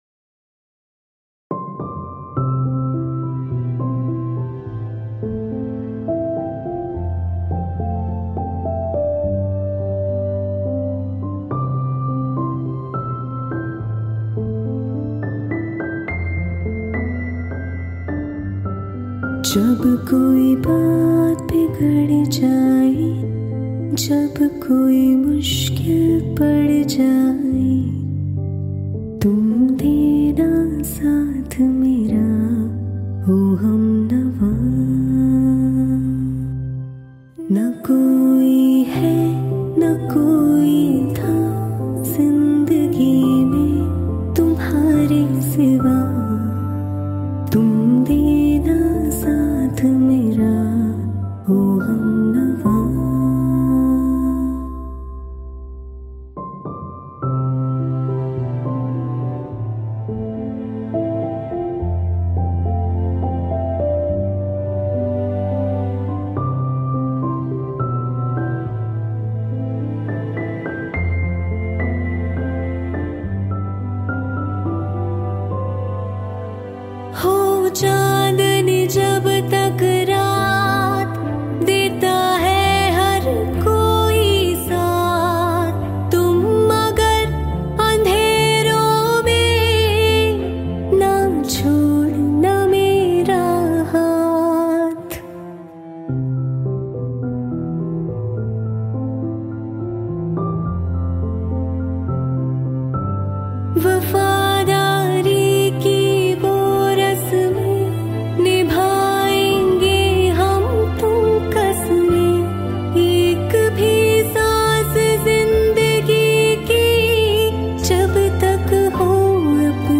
(Unplugged)- Female Version